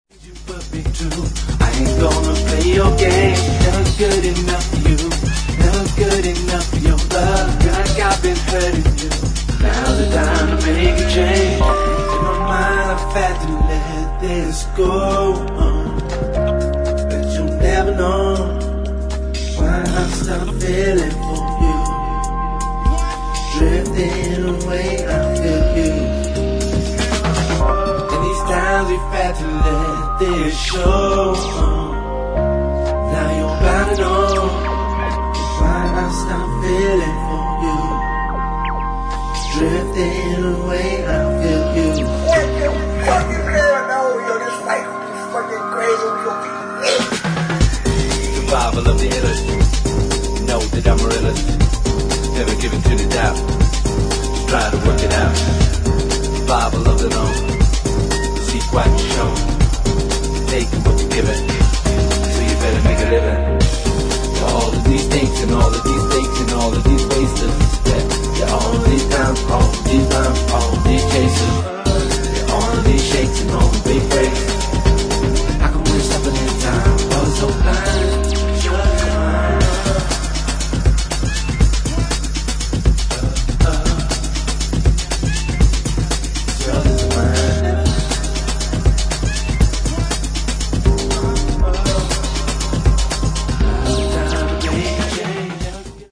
[ HOUSE / BROKEN BEAT ]